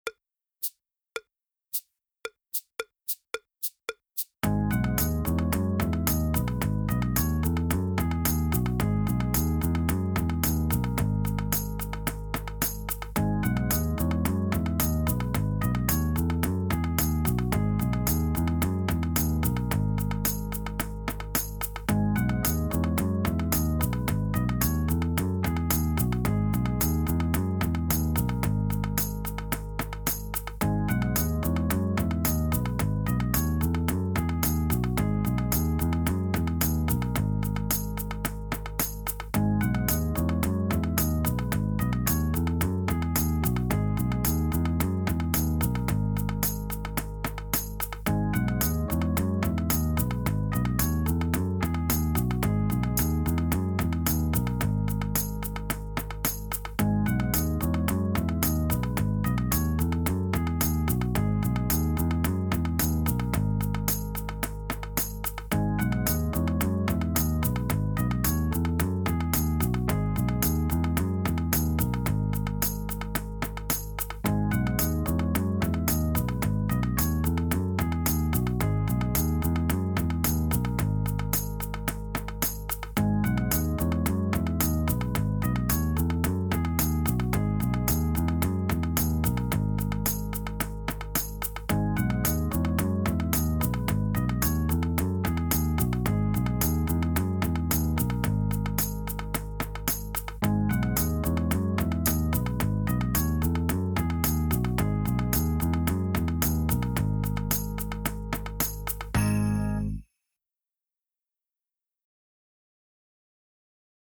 Backing Tracks
Fast fills